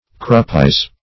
Meaning of kruppize. kruppize synonyms, pronunciation, spelling and more from Free Dictionary.
Search Result for " kruppize" : The Collaborative International Dictionary of English v.0.48: Kruppize \Krupp"ize\, v. t. [imp.